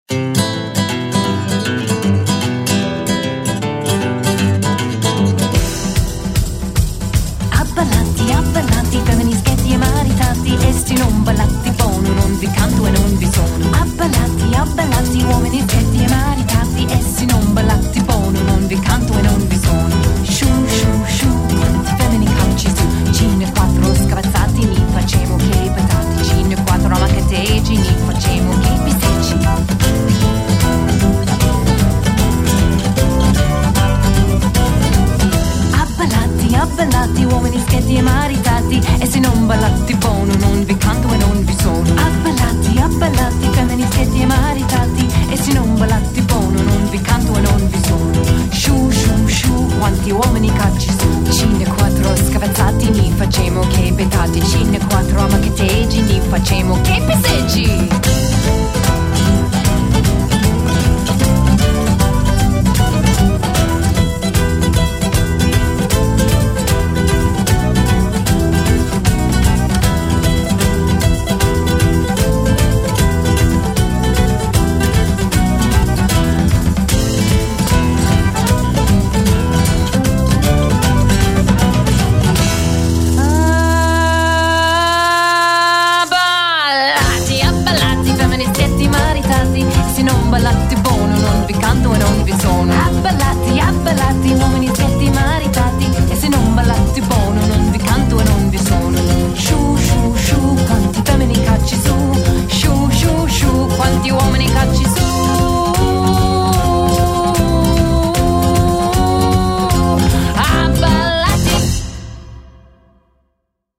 Traditional Sicilian Folk Song
vocals, tamburello
guitar
upright bass
drums and percussion
button accordion